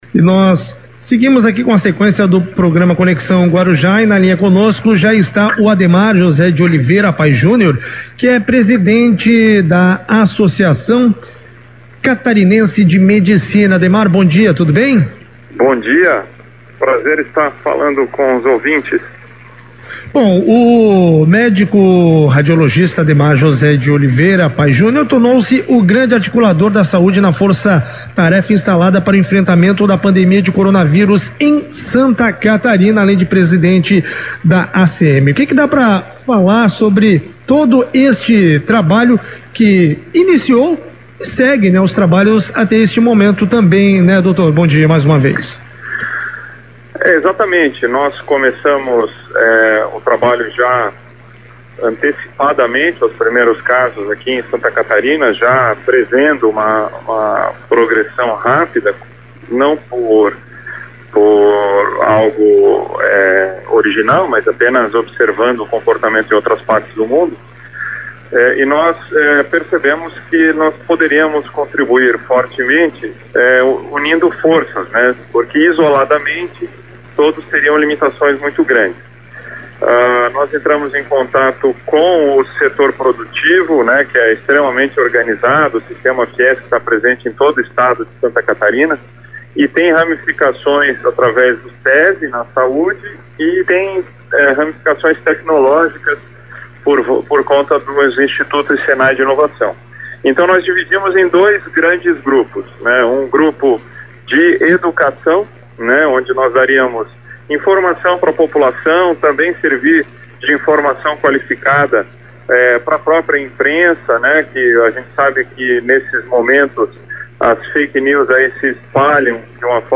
Entrevista Rádio Guaruja: Força-tarefa de enfrentamento ao COVID-19